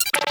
sounds / doors / futuristic